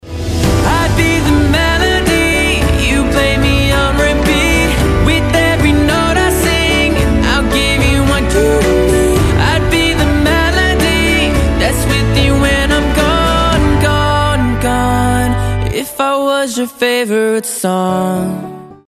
поп
мужской вокал